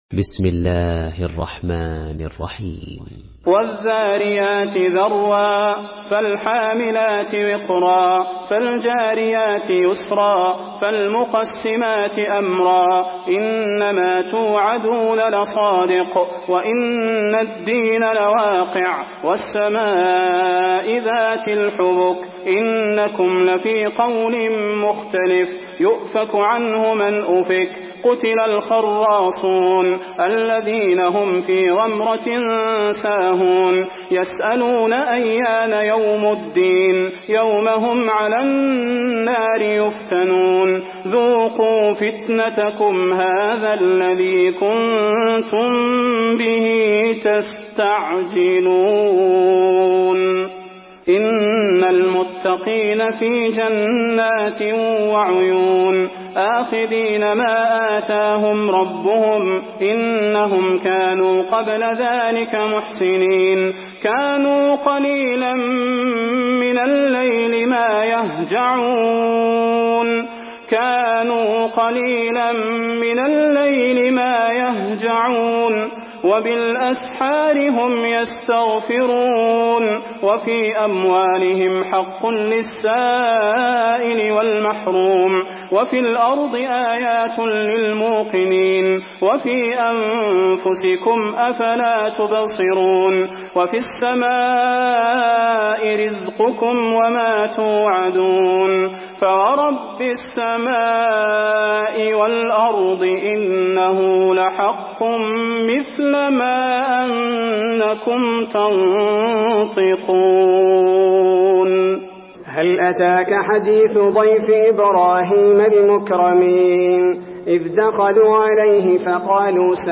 Surah Ad-Dariyat MP3 by Salah Al Budair in Hafs An Asim narration.
Murattal Hafs An Asim